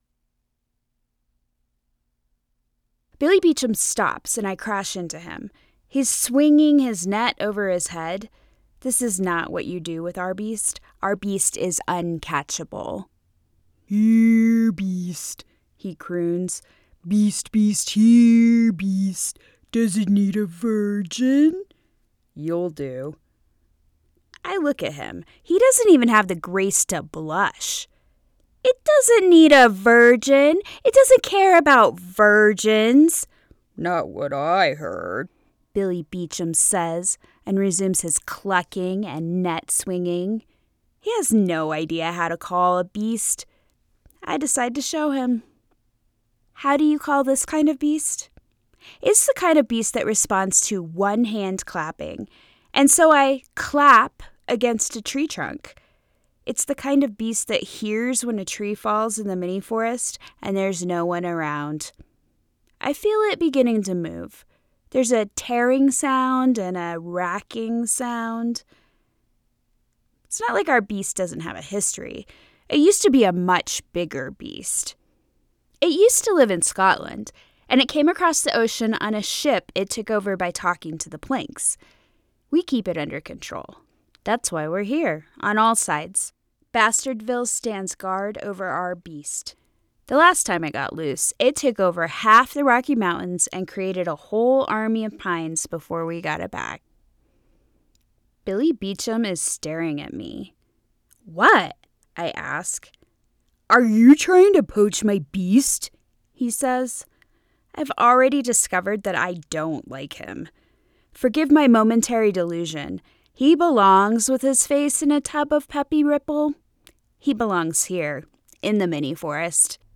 Female
I have been told I have a very youthful sounding voice.
Audiobooks
Ya Fantasy/Horror – 1st Person
Words that describe my voice are Youthful, Clear, Calm.
All our voice actors have professional broadcast quality recording studios.